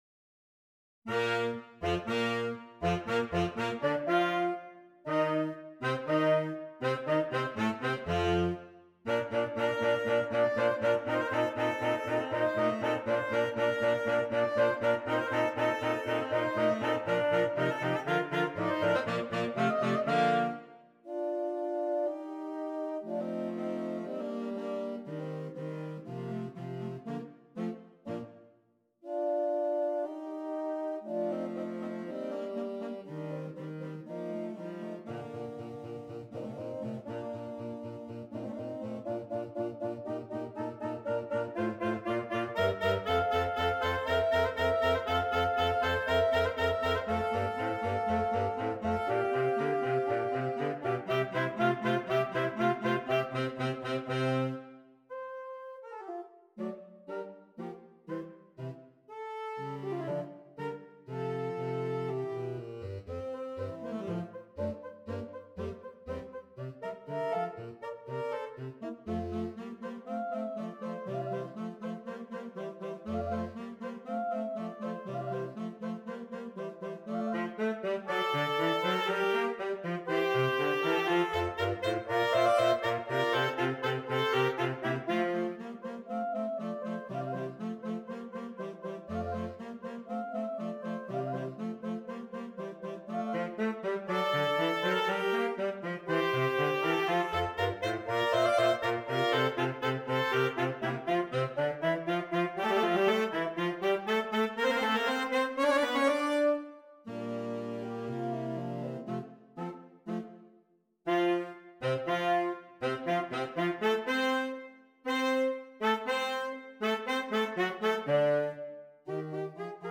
Gattung: Für Saxophonquartett
Besetzung: Instrumentalnoten für Saxophon